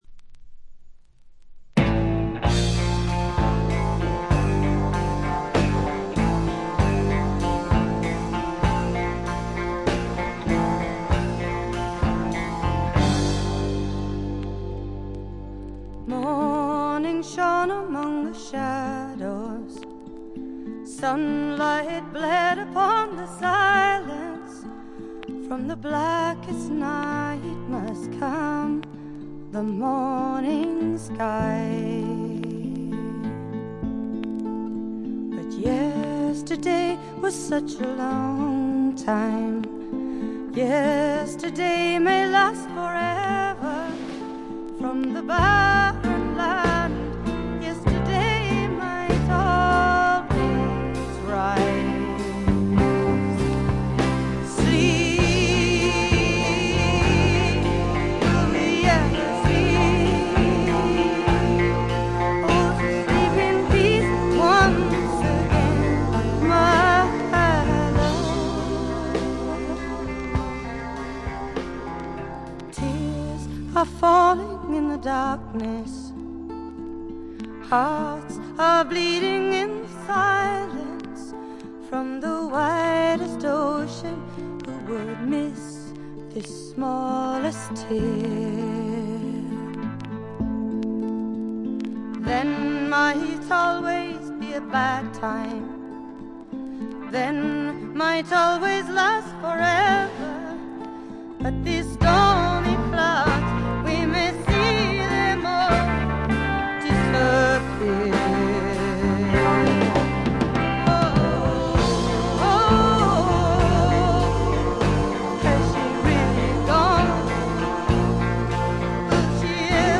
チリプチ多めですが、大きなノイズはありません。
試聴曲は現品からの取り込み音源です。
Recorded and mixed at Olympic Sound Studios, London